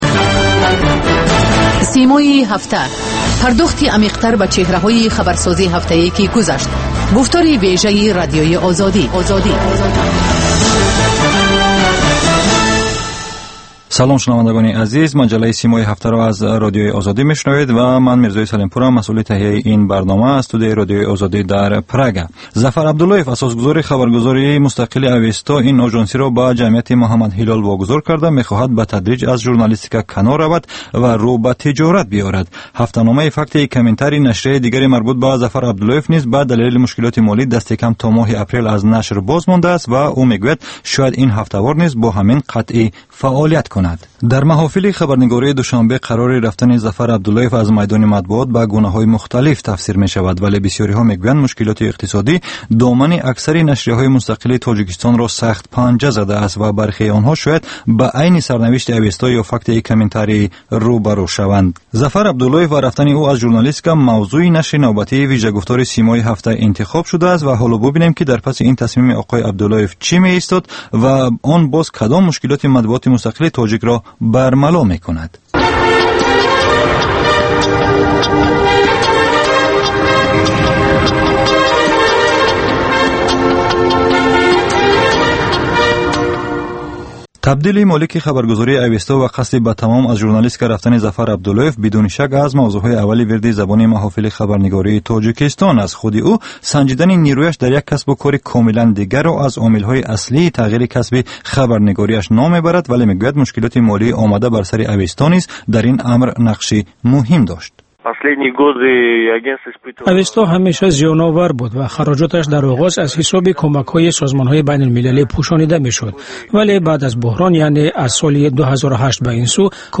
Талоши чеҳранигорӣ аз афроди хабарсоз ва падидаҳои муҳими Тоҷикистон, минтақа ва ҷаҳон. Гуфтугӯ бо коршиносон.